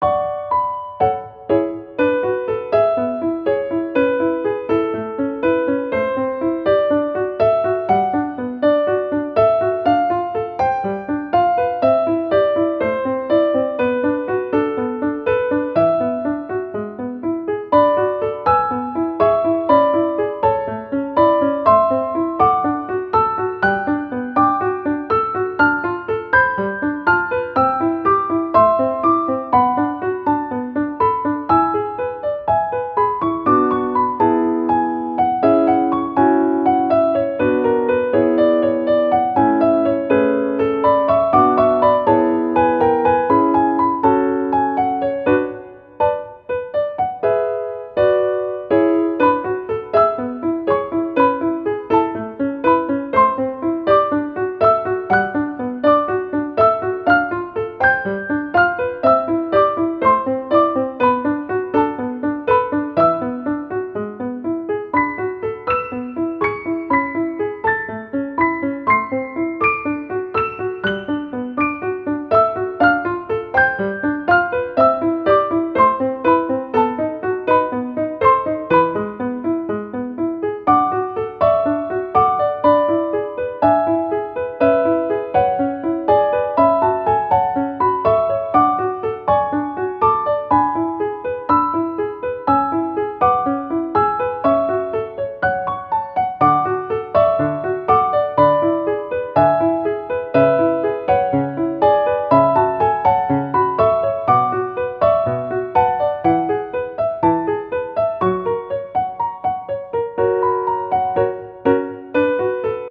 • 明るくほがらかなピアノ曲のフリー音源を公開しています。
ogg(L) 楽譜 軽やか おしゃれ スタッカート
話弾むまったりお洒落空間。